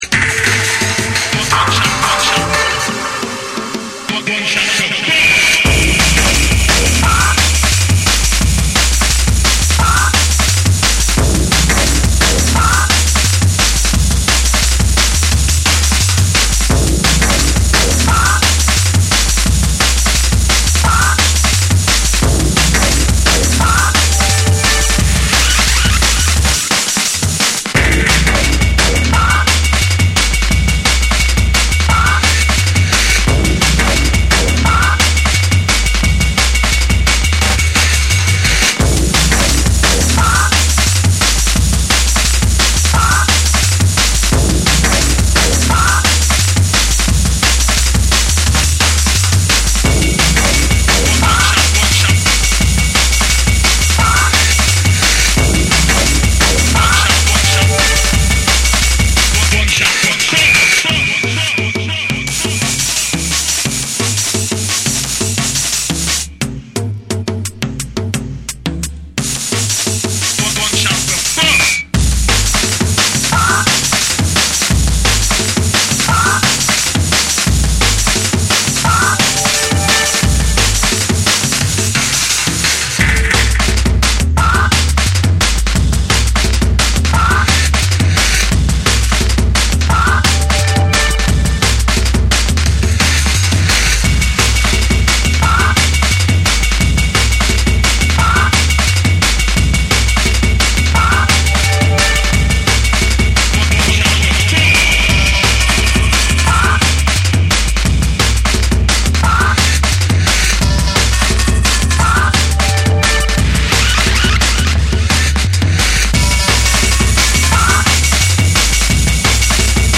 緻密に刻まれるビートと重厚なベースが絡み合い、ダークで硬質なグルーヴを生むドラムンベースを収録。
JUNGLE & DRUM'N BASS